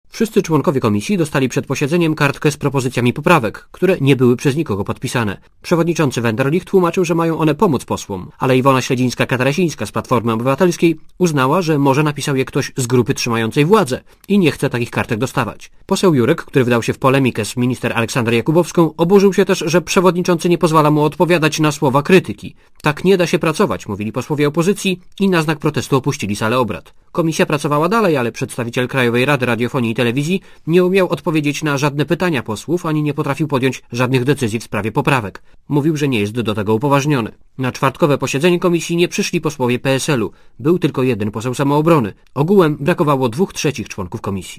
Relacja reportera Radia Zet (348Kb)